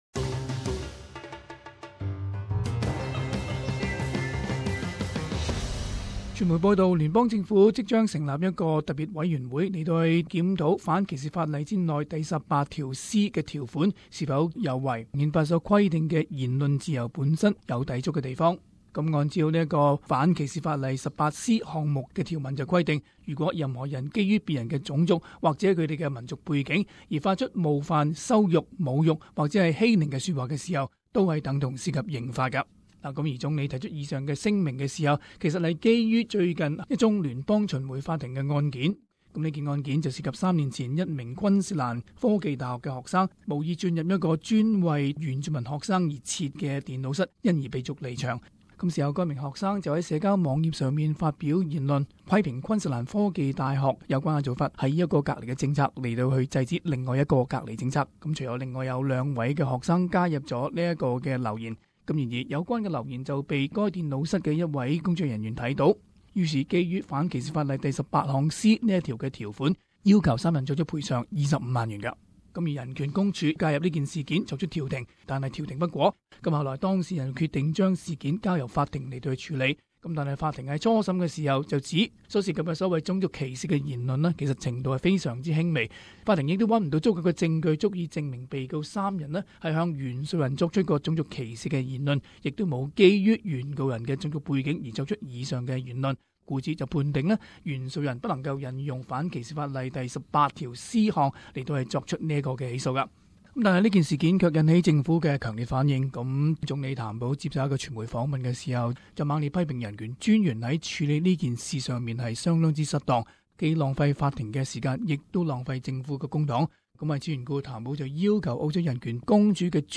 【時事報導】放寬反種族歧視法例18C項？